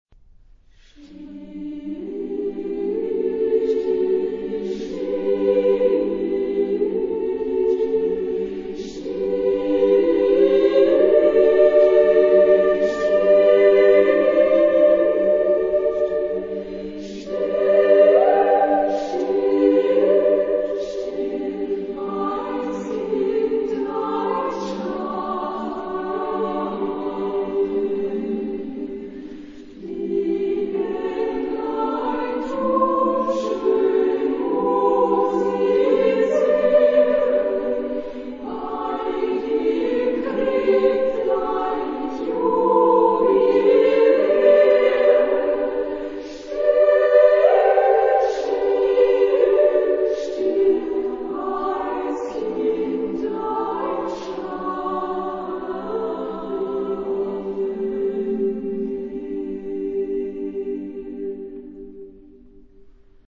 Genre-Style-Forme : Chanson ; Folklore ; Berceuse ; Sacré
Type de choeur : SSAAA  (5 voix égales de femmes )
Solistes : Sopran (1)  (1 soliste(s))
Tonalité : sol majeur